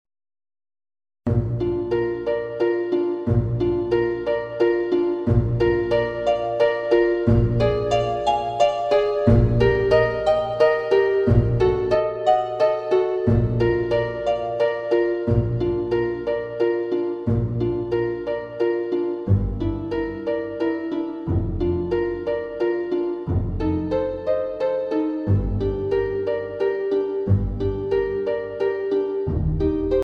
Downloadable and Custom Backing Tracks